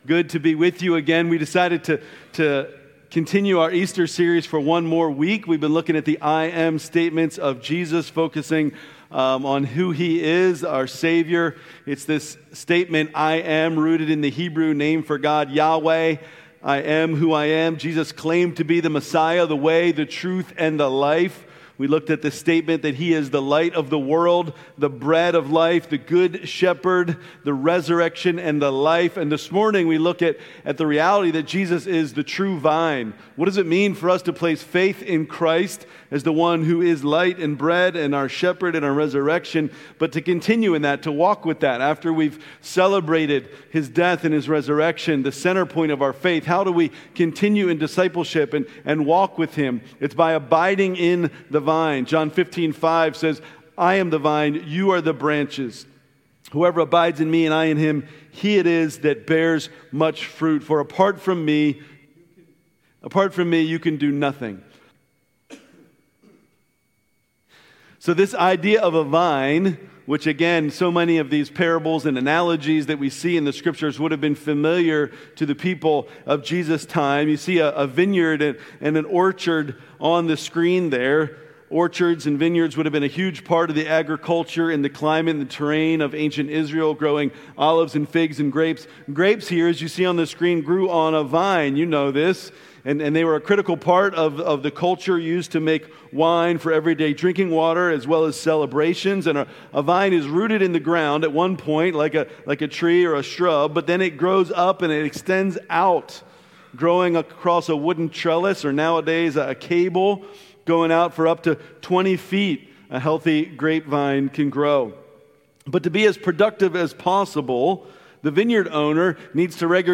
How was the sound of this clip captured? April 12, 2026 – Easter Sunday Worship Service Order of Service: Welcome Call to Worship Praise Songs Community News Children’s Dismissal Worship Song Message L